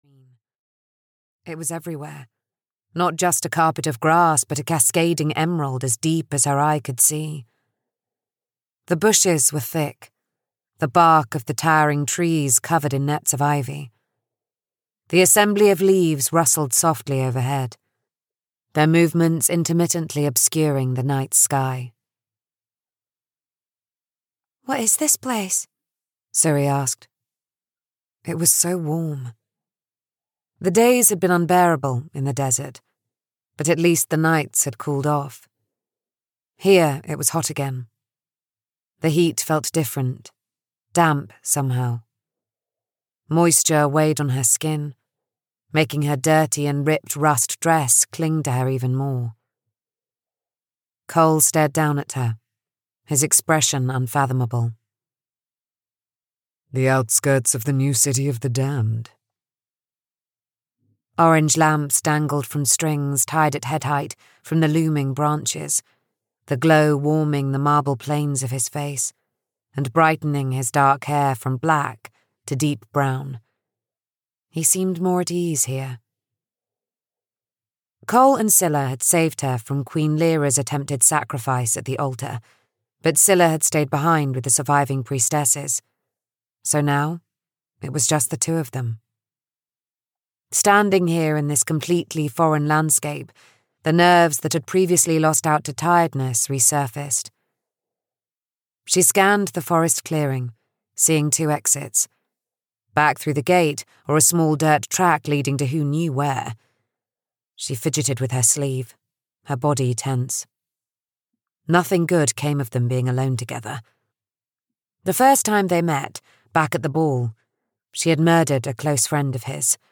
A Promise of Blood (EN) audiokniha
Ukázka z knihy